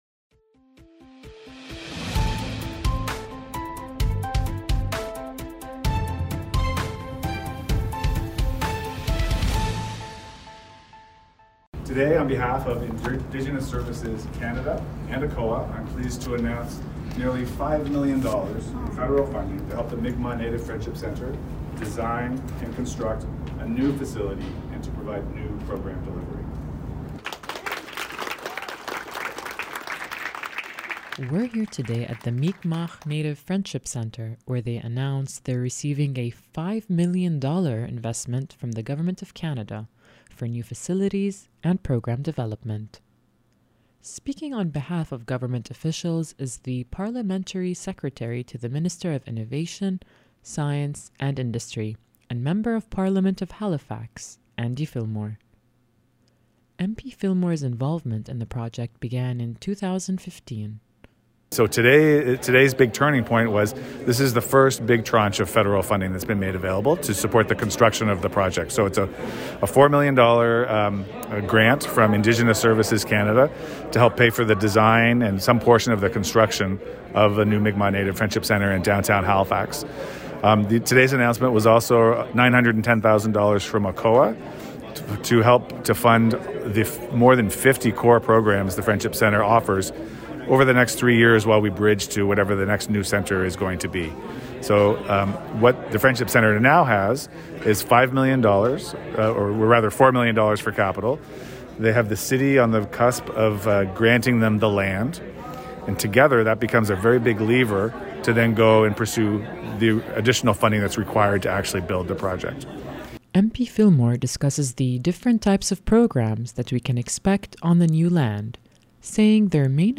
EditedPressConference.mp3